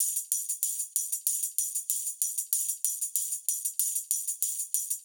SSF_TambProc2_95-01.wav